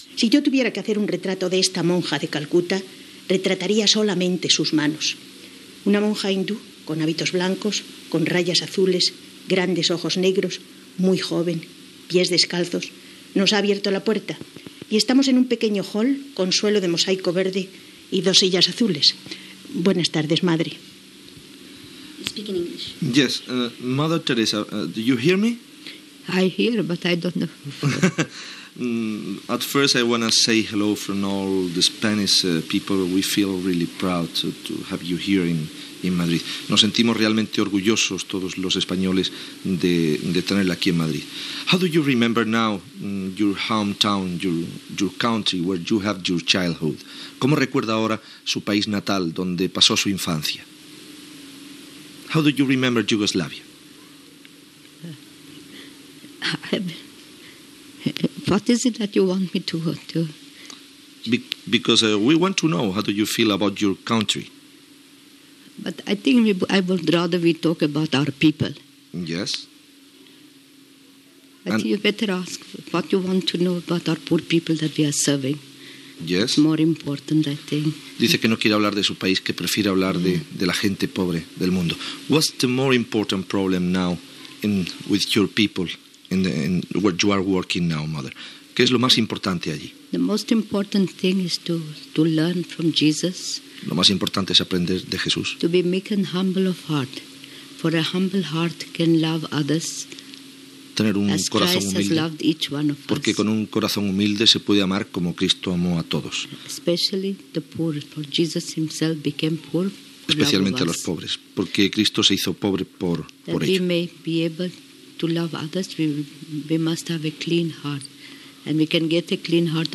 Retrat de la personalitat de la monja mare Teresa de Calcuta, Premi Nobel de la Pau 1979, i entrevista sobre la seva dedicació als pobres en la seva visita a Madrid
Informatiu